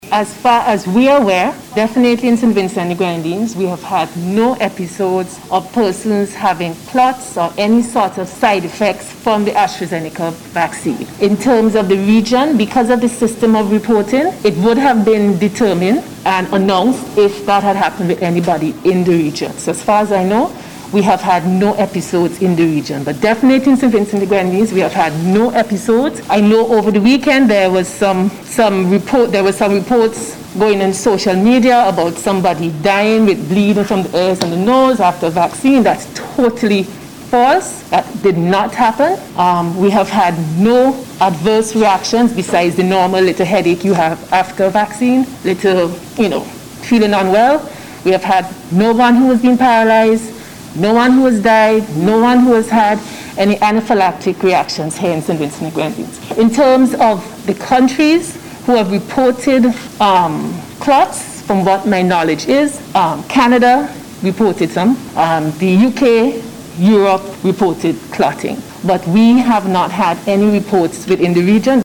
The assurance came from Chief Medical Officer Dr. Simone Keizer-Beache, as she responded to a question from the media, during yesterday’s media briefing, hosted by the Ministry of Health at the Argyle International Airport.